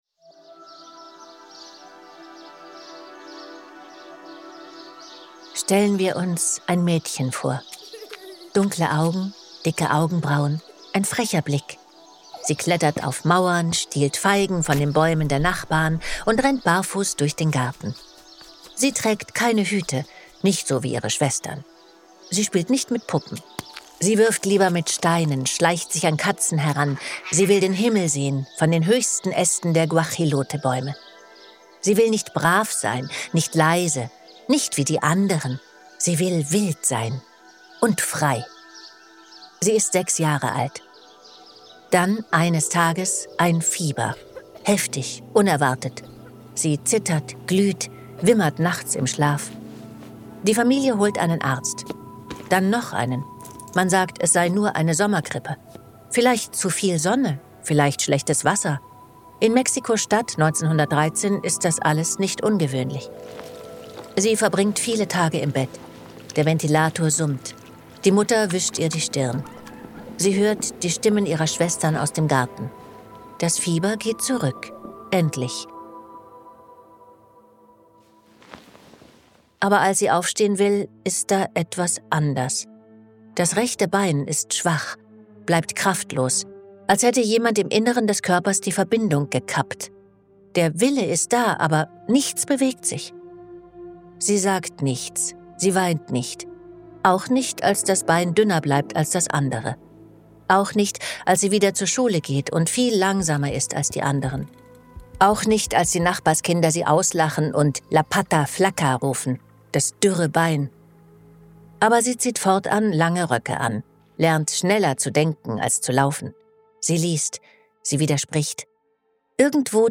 Andrea Sawatzki erzählt in dieser Folge, wie die Medizin die gefährliche Kinderlähmung in den Griff gekriegt hat.